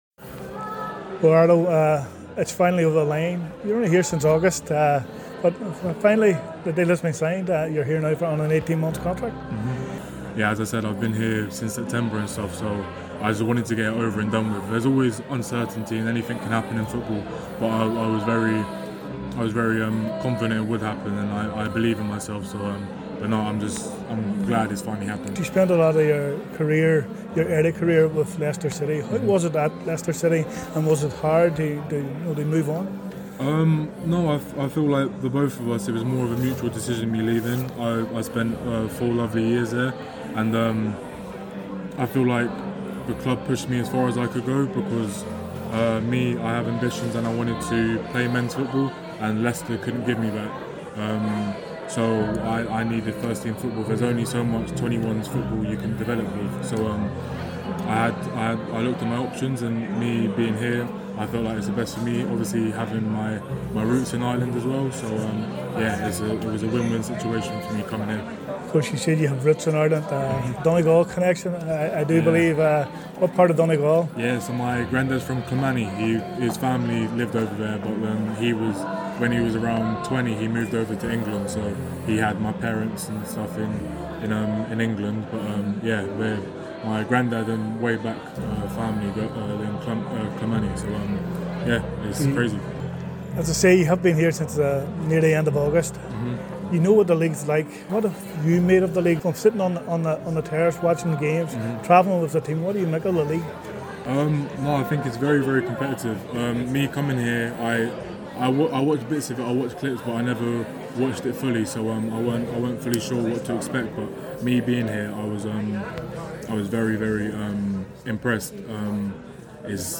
at the Derry City alternative kit launch this week.